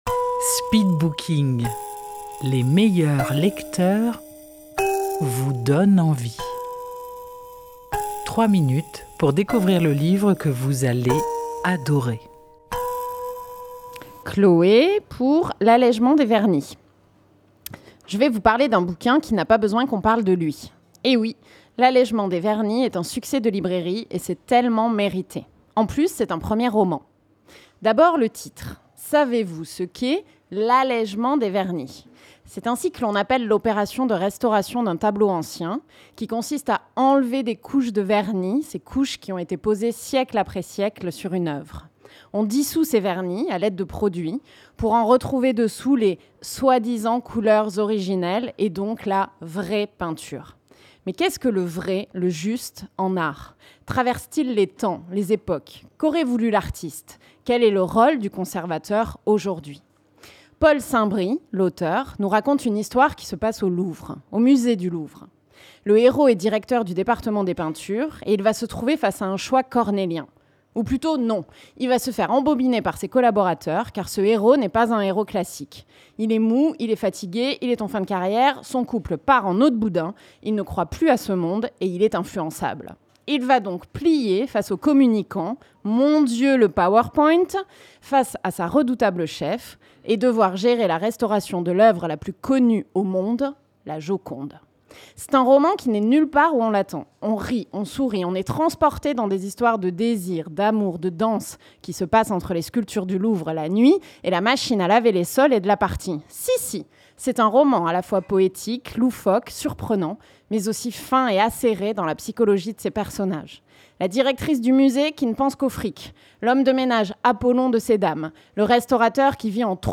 Rendez-vous au Speed Booking : les meilleurs lecteurs et lectrices vous font partager leur passion pour un livre en 3 minutes chrono et en public.
Enregistré en public au Bar & Vous à Dieulefit.